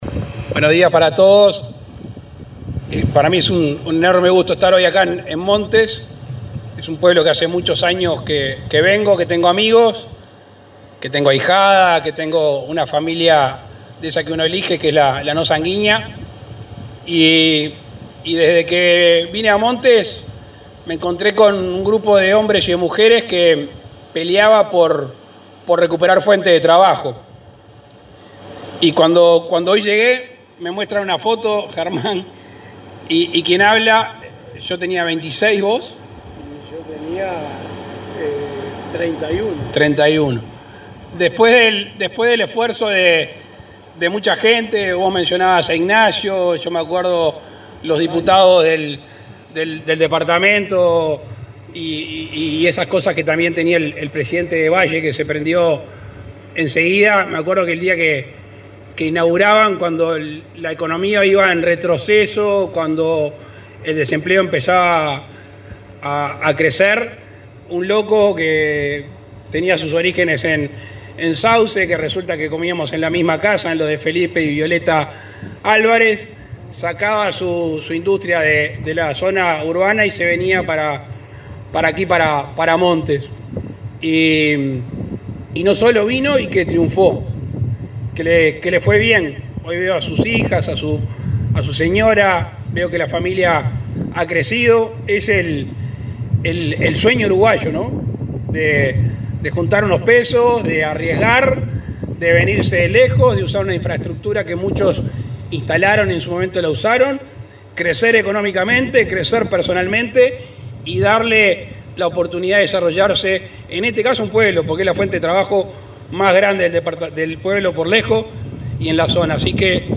Oratoria del presidente Luis Lacalle Pou
Oratoria del presidente Luis Lacalle Pou 12/11/2021 Compartir Facebook X Copiar enlace WhatsApp LinkedIn En el marco de los festejos por los 130 años de la localidad de Montes, Canelones, el presidente Luis Lacalle Pou visitó este viernes 12 la planta de Doña Coca, donde inauguró una nueva fuente de energía con paneles fotovoltaicos.